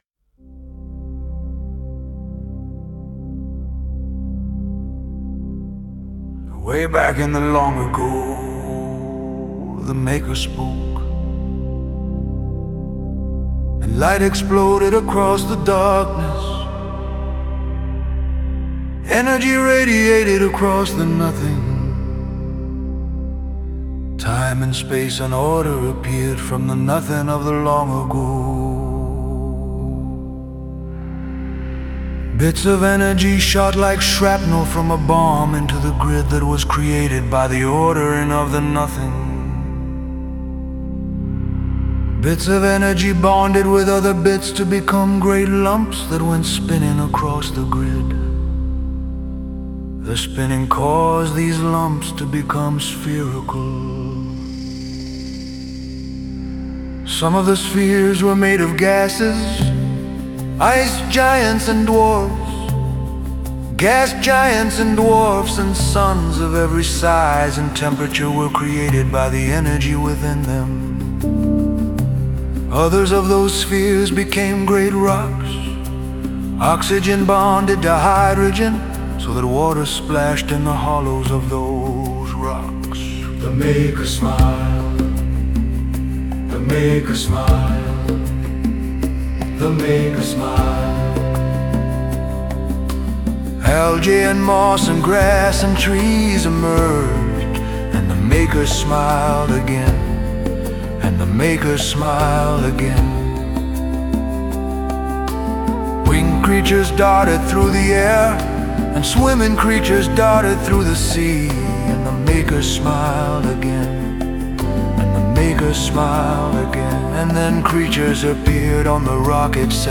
“Way Back in the Long Ago” is a campfire story told by a group of old men.